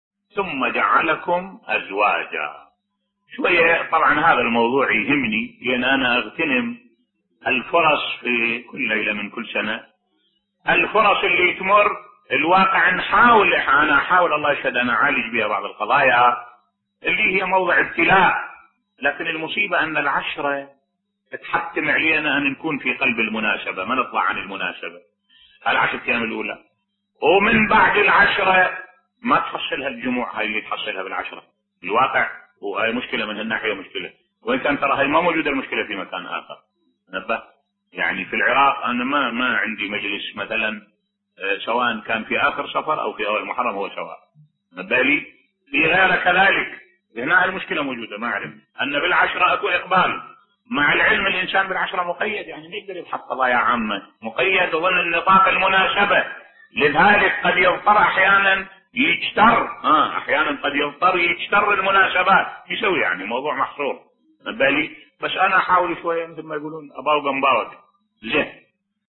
ملف صوتی لماذا تكون مجالس الحسين مزدحمة فقط في العشرة الأولى من محرم بصوت الشيخ الدكتور أحمد الوائلي